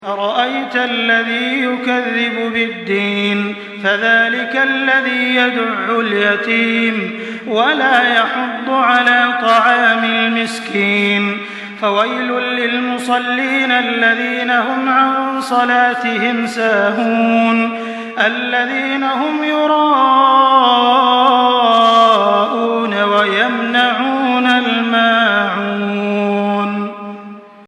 Surah Al-Maun MP3 by Makkah Taraweeh 1424 in Hafs An Asim narration.
Murattal Hafs An Asim